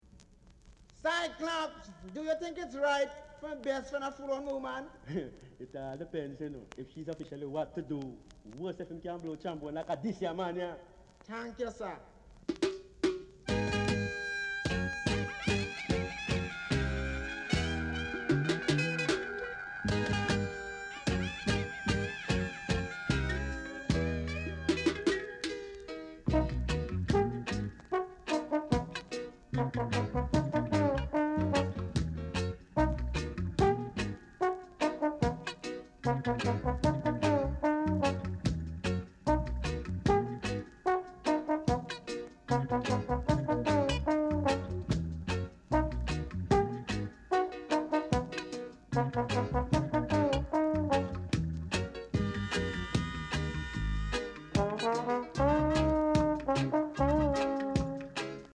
Regae Inst
Tuff inst!